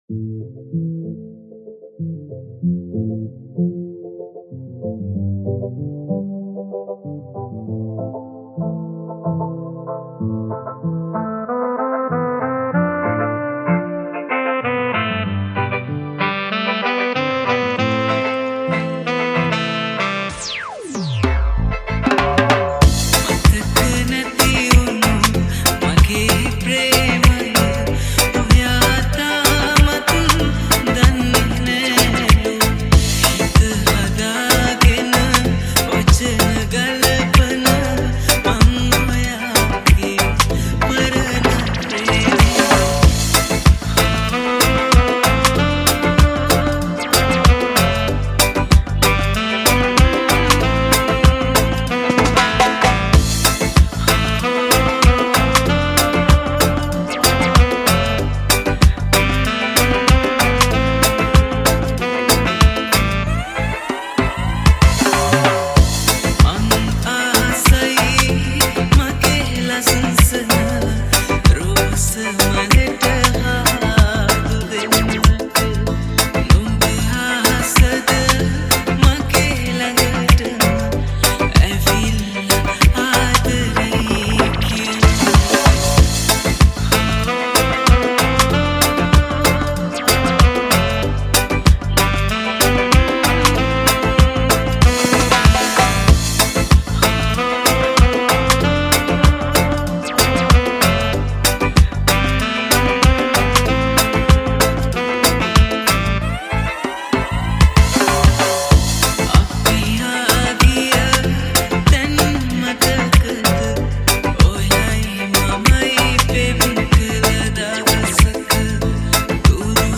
Remix New Song